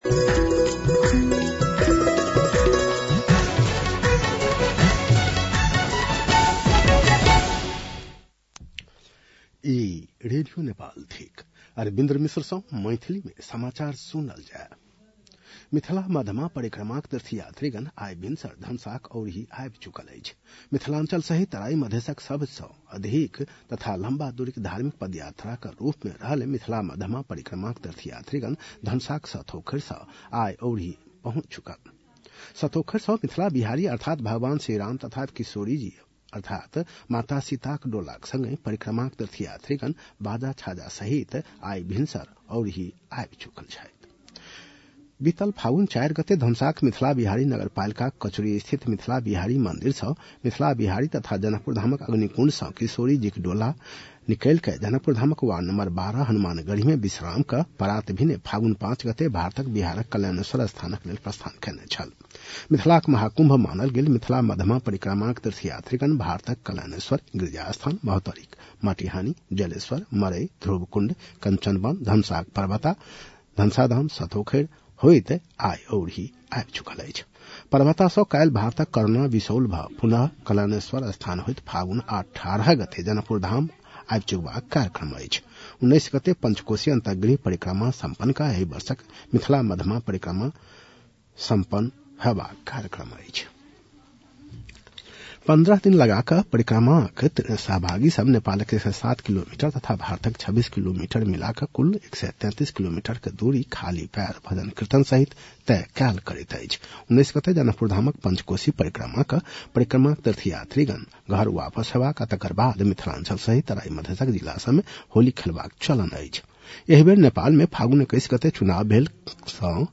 An online outlet of Nepal's national radio broadcaster
मैथिली भाषामा समाचार : १५ फागुन , २०८२